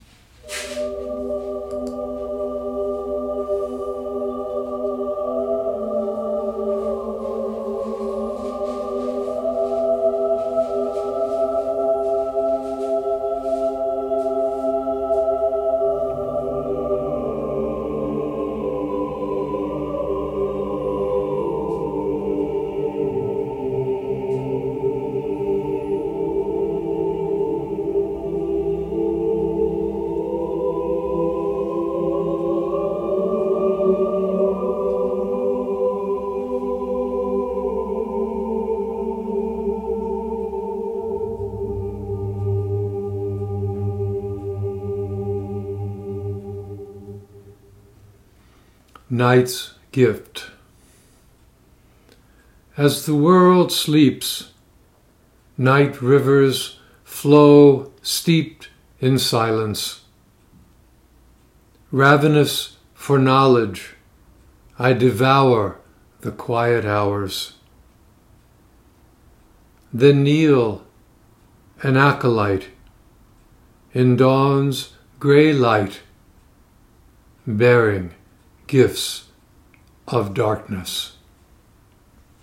Reading of “Night’s Gift” with music by St. Petersburg Chamber Choir